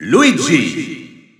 French Announcer announcing Luigi.
Luigi_French_Announcer_SSBU.wav